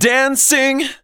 Index of /90_sSampleCDs/Techno_Trance_Essentials/VOCALS/SUNG/C#-BAM